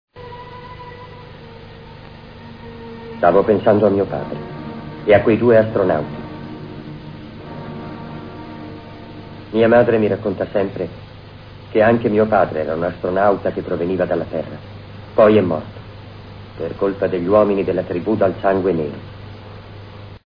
dal telefilm "Megaloman", in cui doppia Yuki Kitazume.